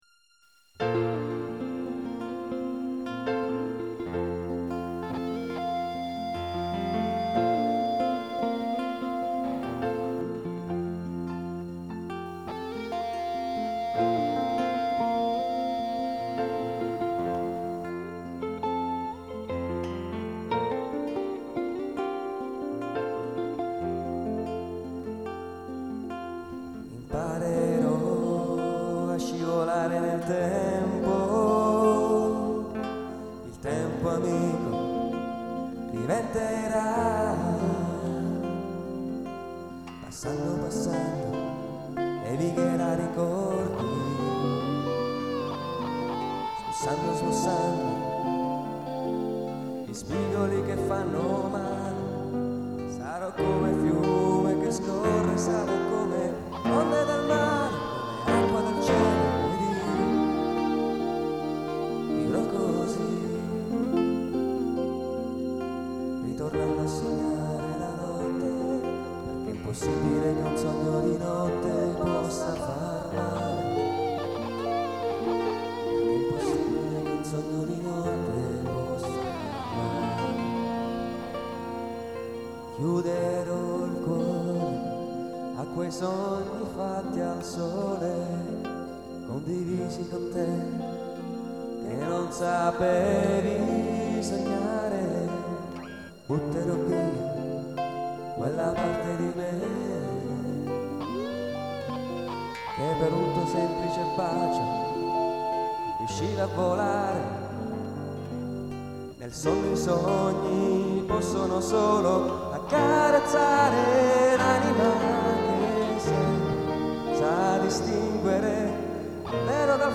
Piano,tastiere,voce e cori
Chitarra acustica e elettrica